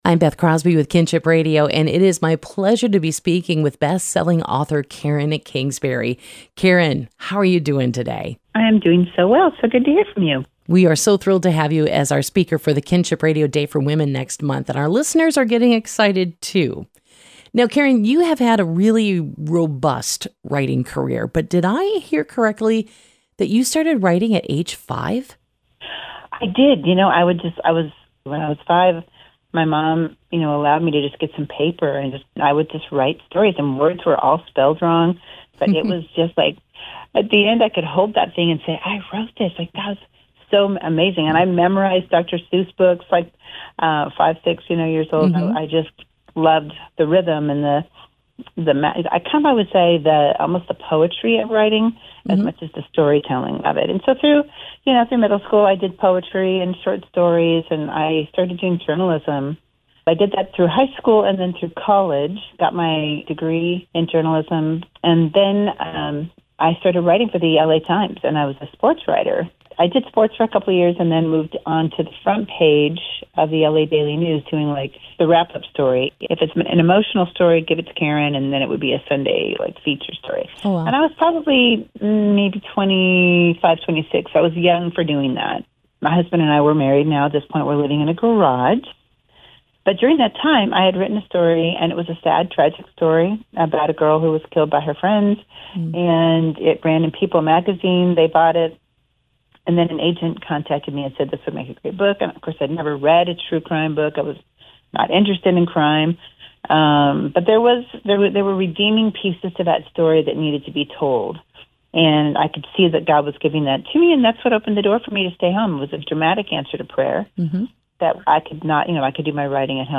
INTERVIEW-Karen-Kingsbury-part-one.mp3